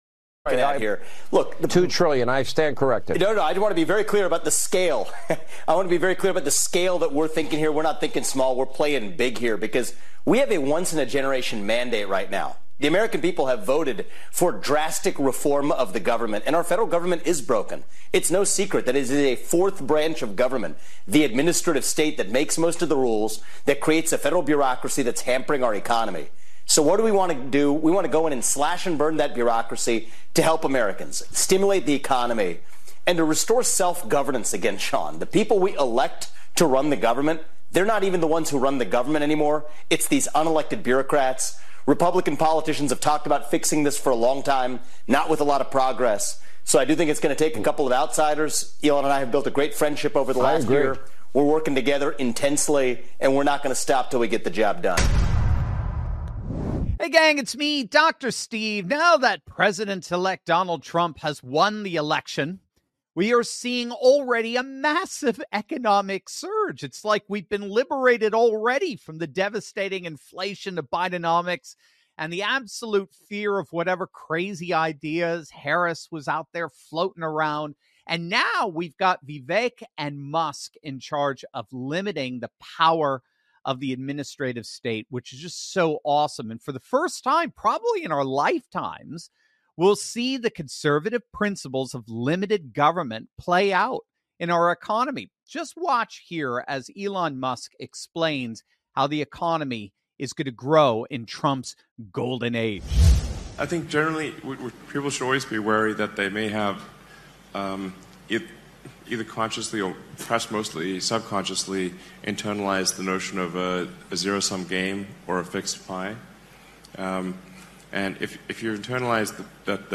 The text also features a conversation with trading expert